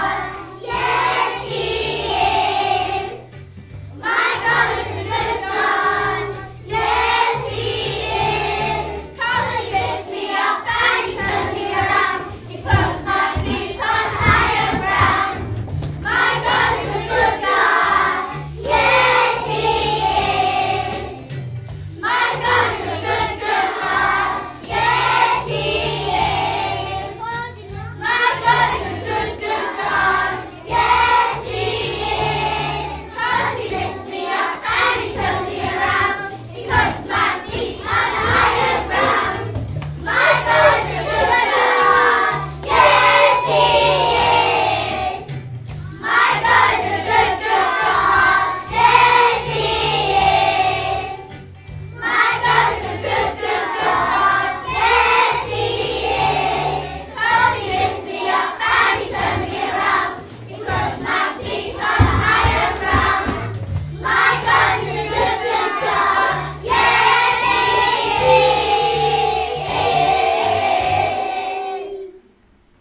KS1 fun singing!